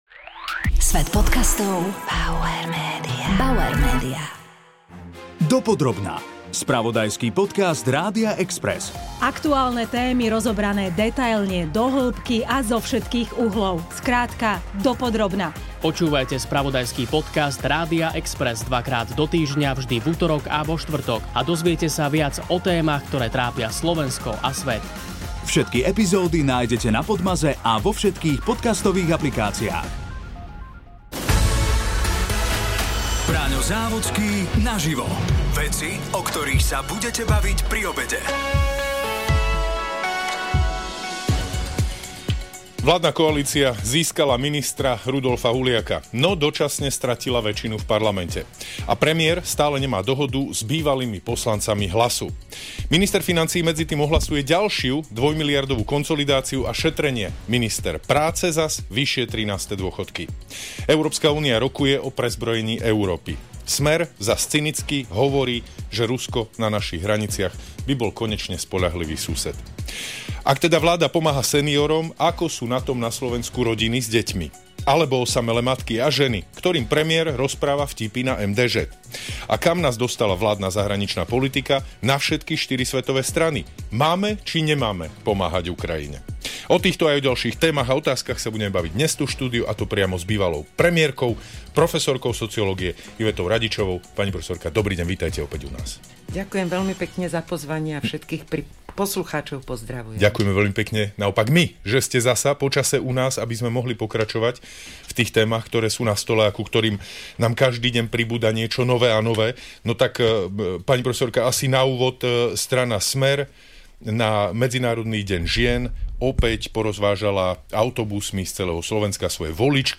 Braňo Závodský sa rozprával bývalou premiérkou a profesorkou sociológie Ivetou RADIČOVOU.